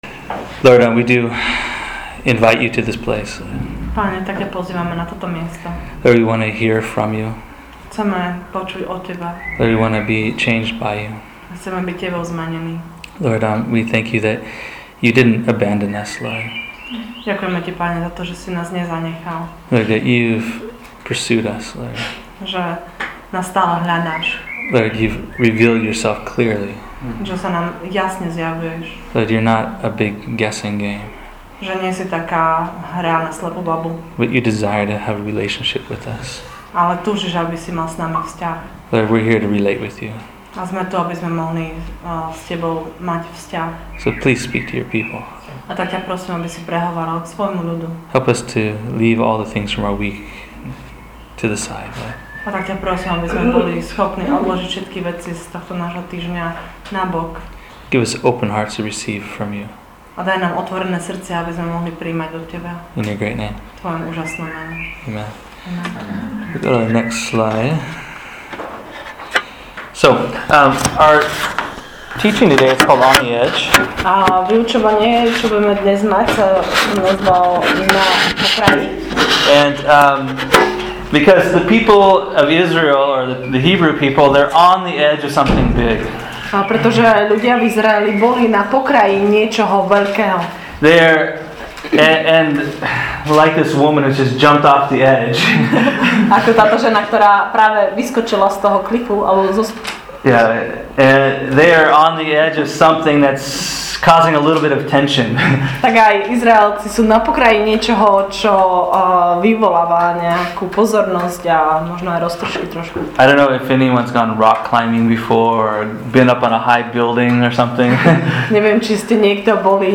Check out this teaching from Exodus 13:17-22 entitled “On the Edge” to discover how we can know His will and direction for our lives.